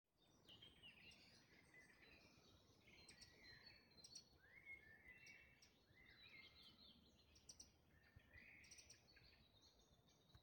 Statt seines Gesangs gab er nun Warnrufe von sich.
Zaunkoenig-Stimme-2.mp3